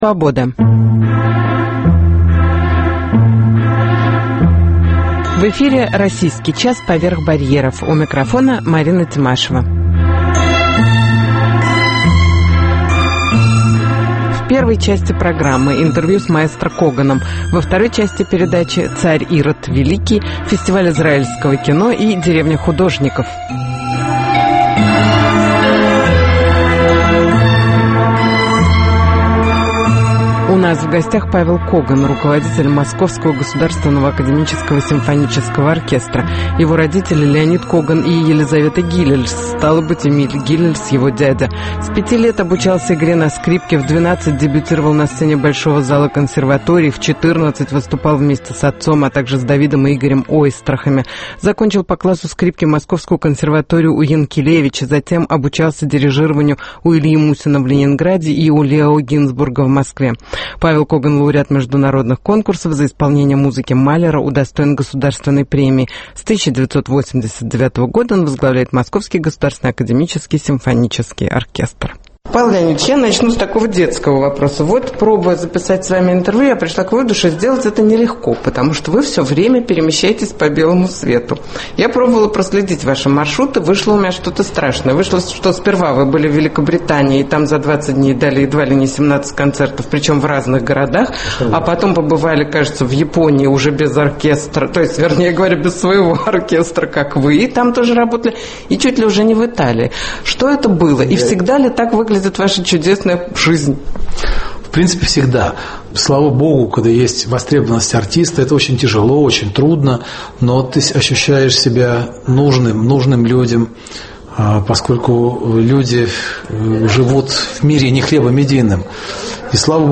Интервью с Маэстро Коганом